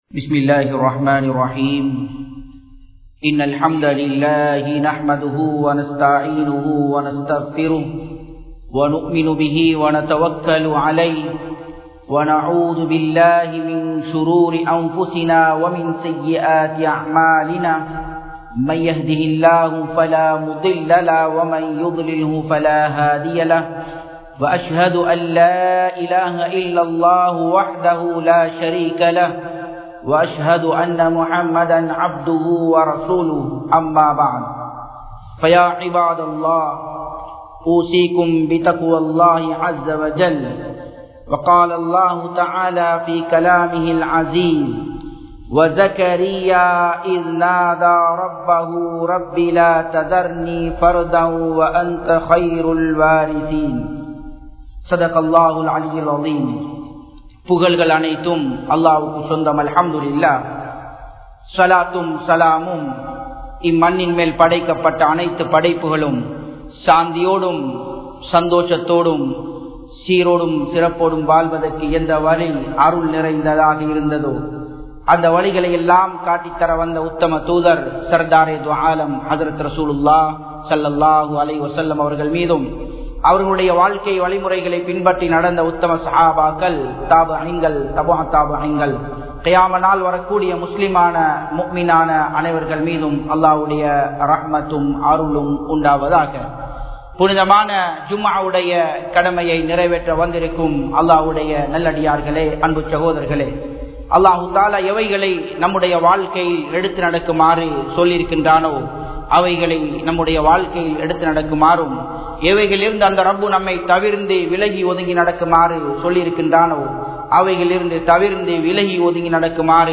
Kulanthai Paakkiyam (குழந்தைப் பாக்கியம்) | Audio Bayans | All Ceylon Muslim Youth Community | Addalaichenai